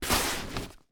household
Duffle Bag Slide Cement 2